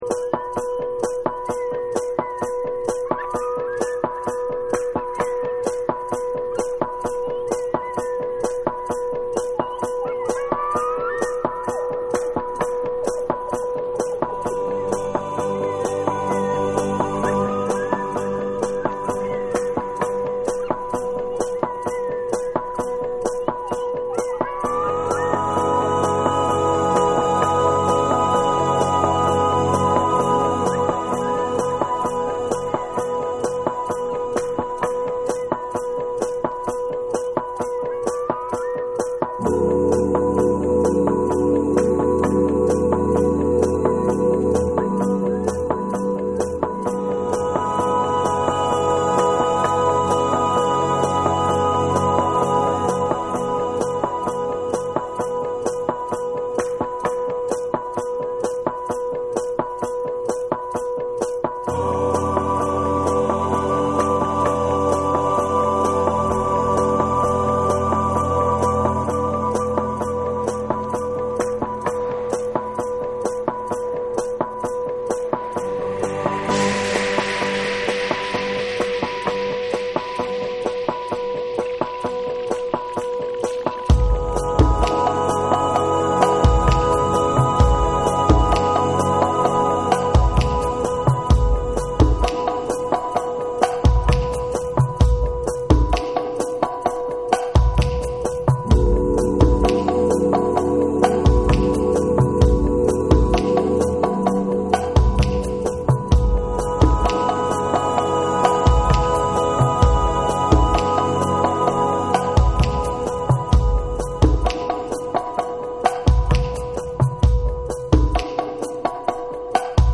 神秘的でアンビエント感溢れるサウンドを展開する3。
TECHNO & HOUSE / JAPANESE